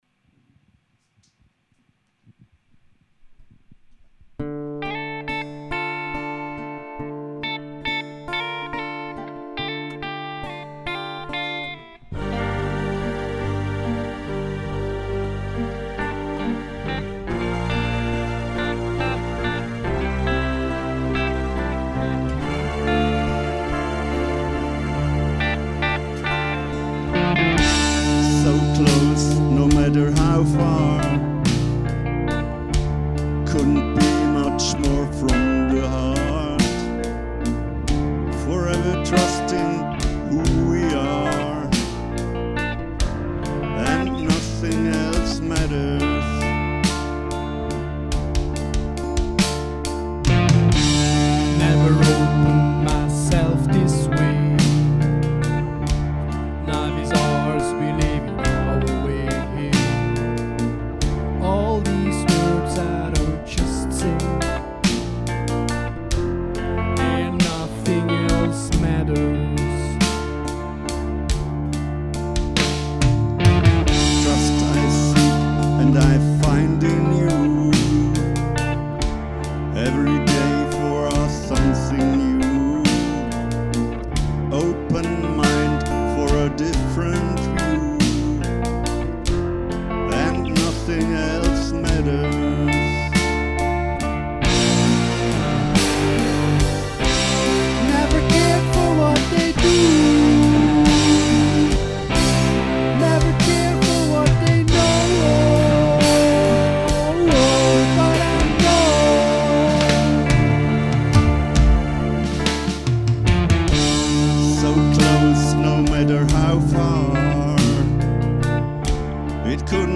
Den wahrscheinlich langsamsten und einfühlsamsten Song